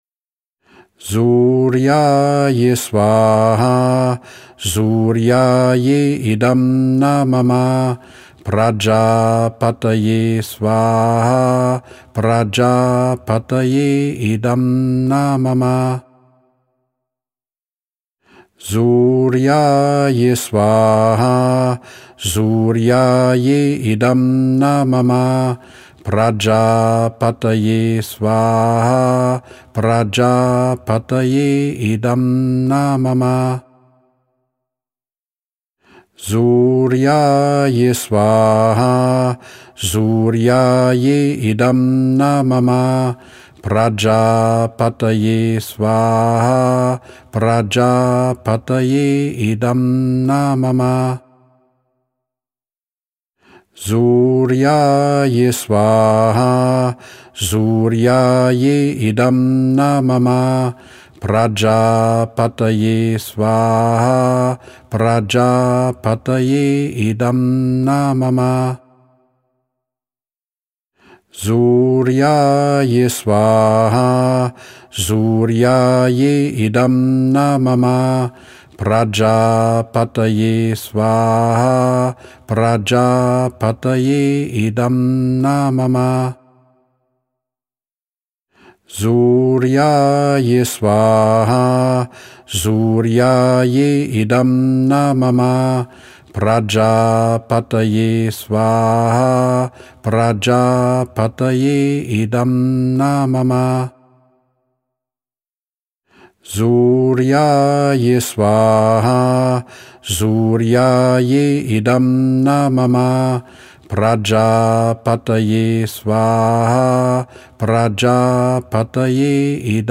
Vedische Feuerzeremonien
Die zu Sonnenauf- und Sonnenuntergang bei der Durchführung von Agnihotra gesungenen Sanskrit- Mantras stehen in Resonanz mit dem Biorhythmus der Sonne.
Agnihotra-Und-Yagna-Mantras-01-Sonnenaufgang-9-Mal.mp3